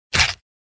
Звуки удара ножом
Резко вонзили нож